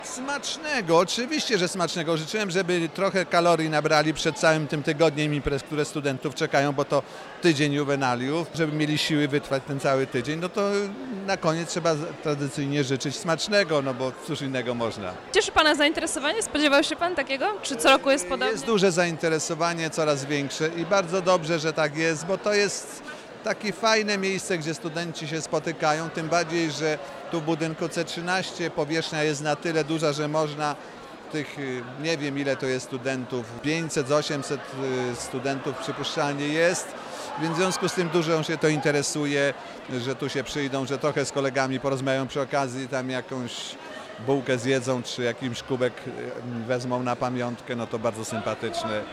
- Przez najbliższy tydzień bawcie się, bawcie i tylko bawcie - radził profesor Tadeusz Więckowski, rektor Politechniki Wrocławskiej. Studentom życzył dobrej zabawy i by nikomu nic złego się nie stało.
rektor.mp3